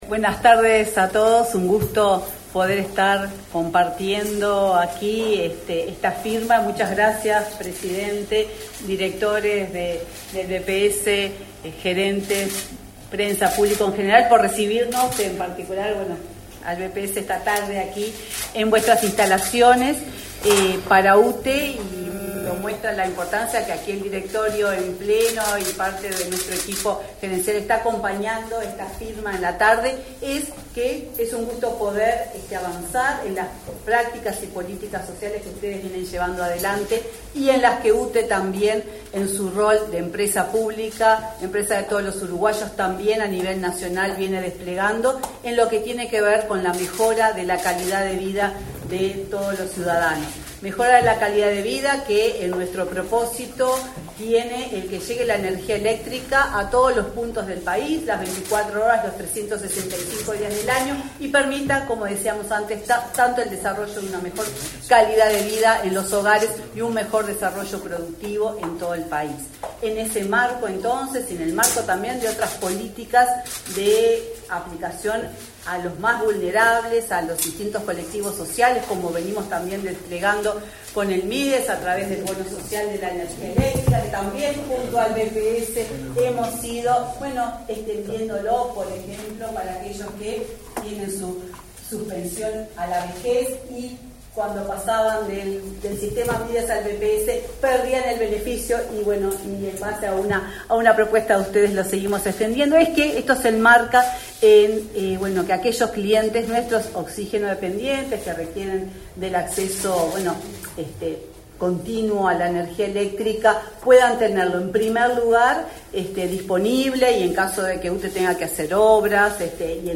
Acto por el convenio entre BPS y UTE por tarifa diferencial para oxigenodependientes
El Banco de Previsión Social (BPS) y la UTE firmaron, este 22 de noviembre, el convenio para facilitar el acceso a tarifa diferencial de electricidad a pacientes oxigenodependientes beneficiarios del BPS. Participarán del compromiso la presidenta de UTE, Silvia Emaldi, y el presidente del BPS, Alfredo Cabrera.